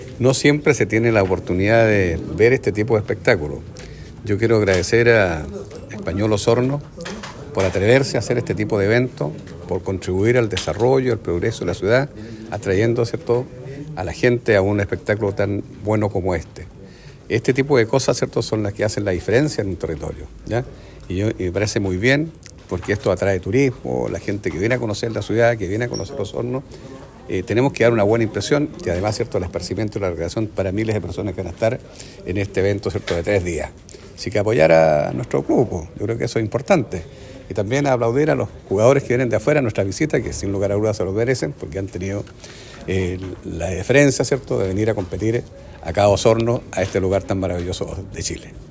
El alcalde Jaime Bertin destacó la relevancia del evento deportivo, invitando a la comunidad a asistir a los partidos, ya que no solo representan una oportunidad única de entretenimiento, sino que también impulsan el desarrollo económico y turístico de la zona.